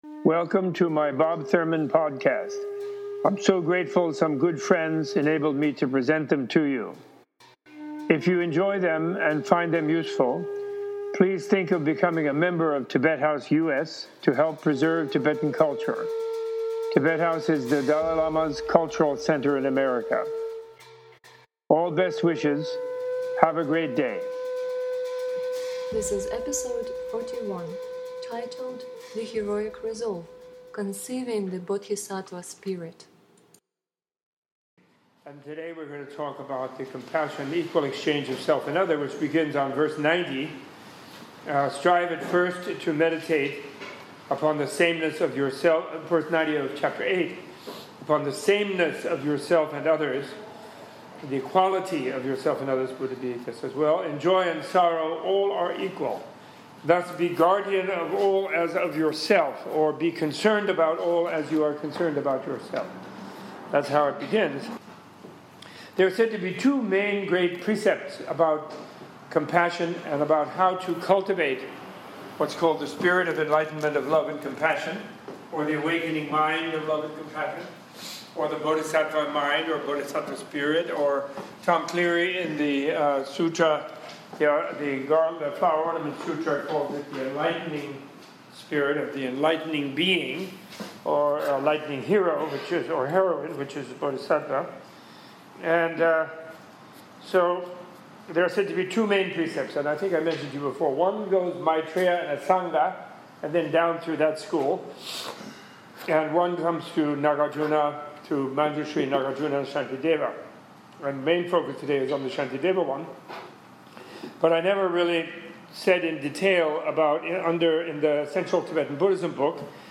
This episode was recorded on October 27, 2015 in a class taught by Professor Thurman.